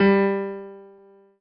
piano-ff-35.wav